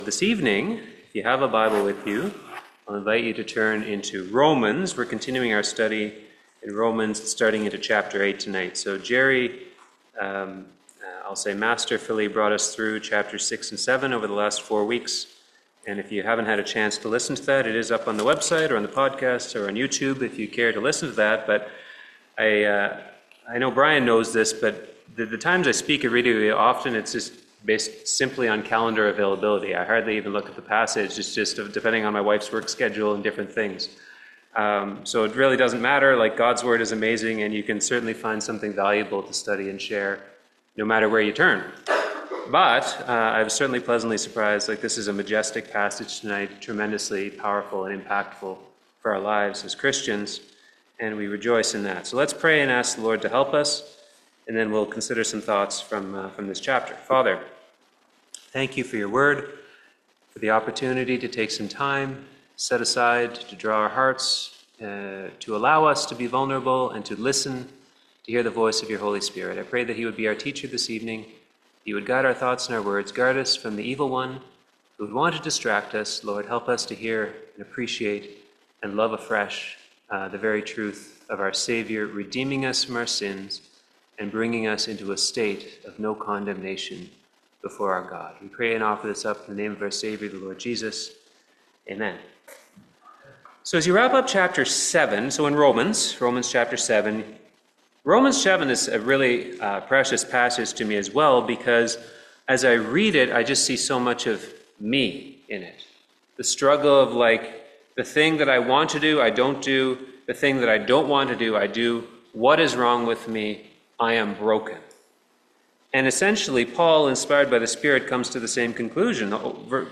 Service Type: Seminar